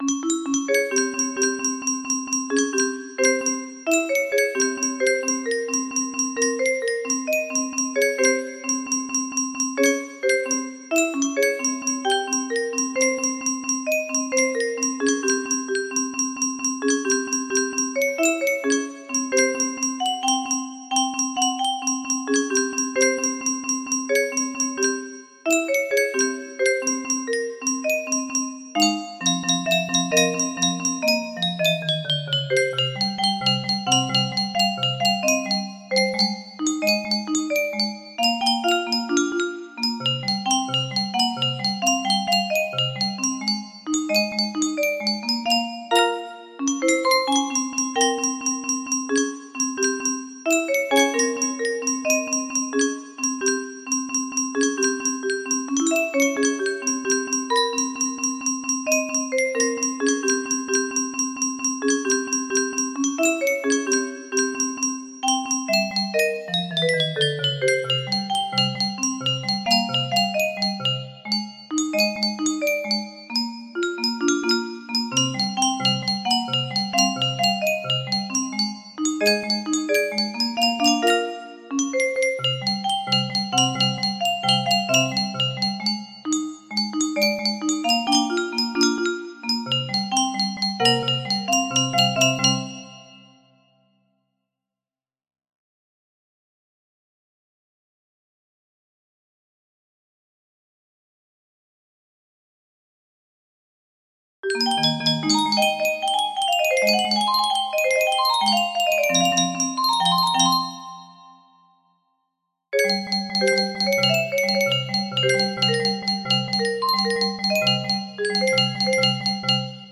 Unknown Artist - Untitled music box melody
Full range 60
Imported from MIDI from imported midi file (20).mid